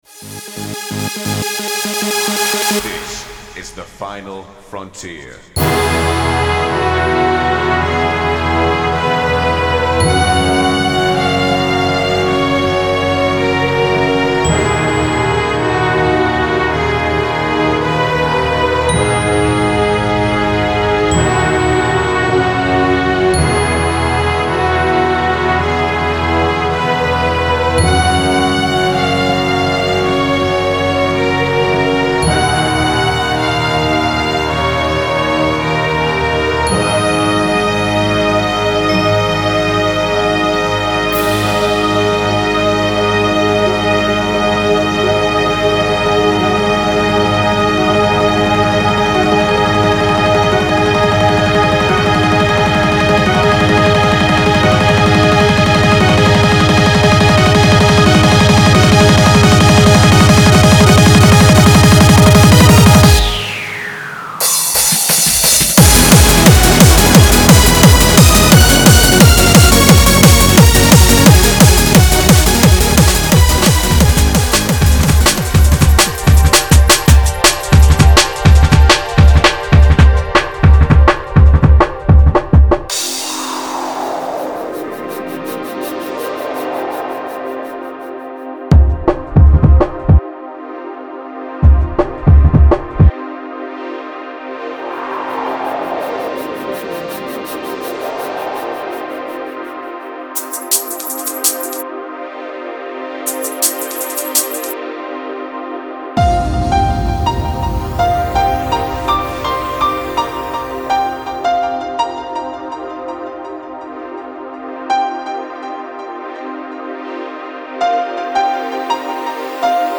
オーケストラ？民族音楽？ともかくやりたい放題でいつも通り！
[Crossfade Demo]